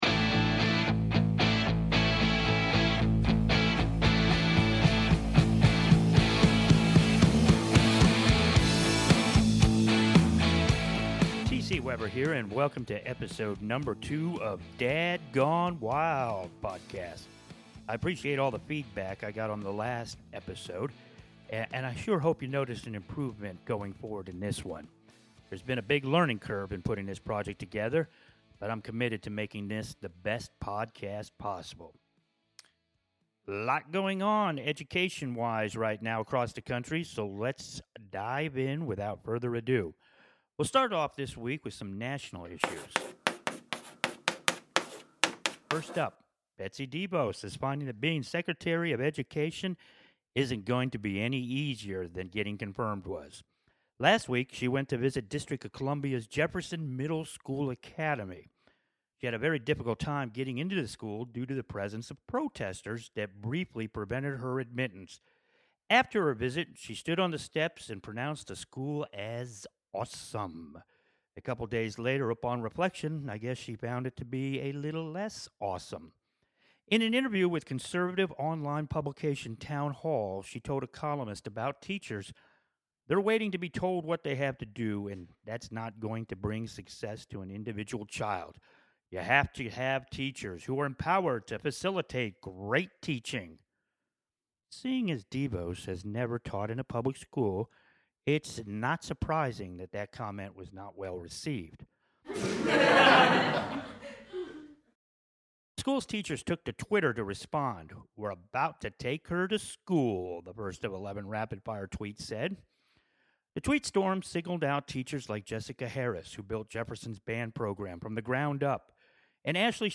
I listened tio the feedback and I think this week's podcast is a little less rough. In this episode I mention Betty Devos, Tennessee State bills, a new advocate for community schools, and MNPS takes a trip. Hopefully it's much improved.